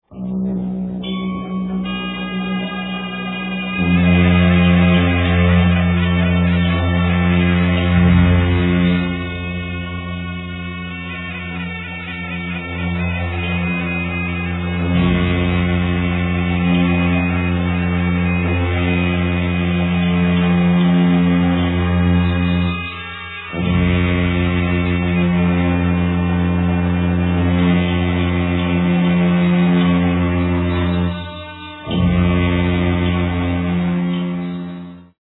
Richly diverse recordings of incredible sonic fidelity!